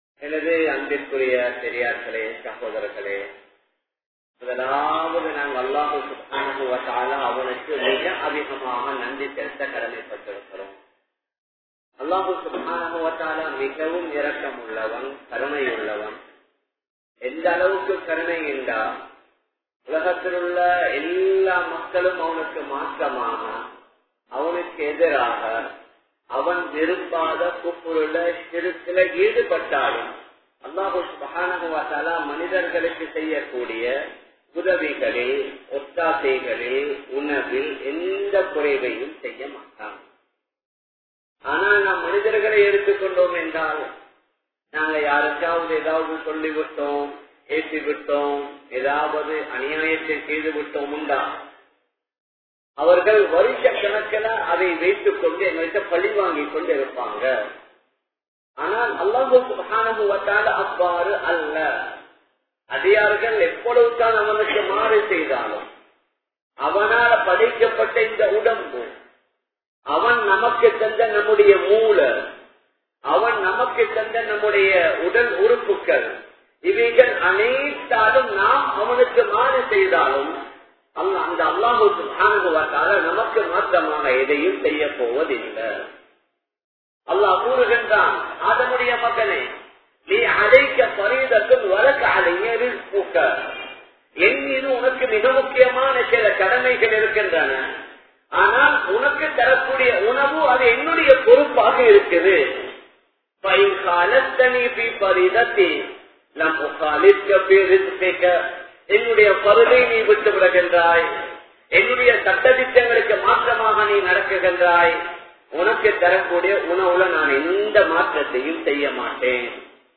Kan Kavarum Suwarkam! (கண் கவரும் சுவர்க்கம்!) | Audio Bayans | All Ceylon Muslim Youth Community | Addalaichenai
Colombo, GrandPass Markaz